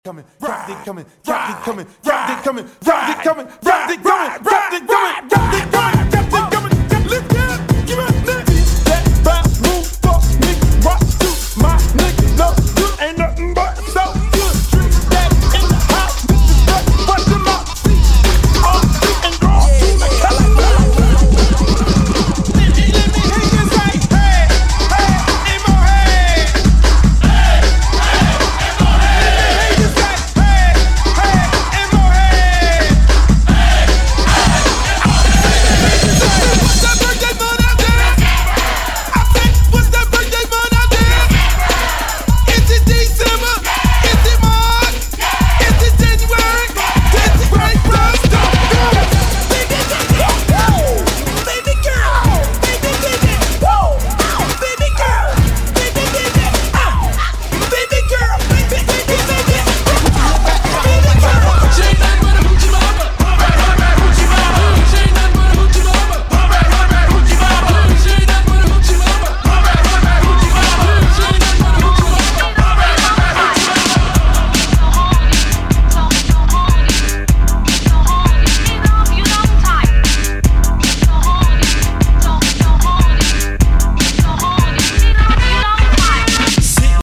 Miami bass!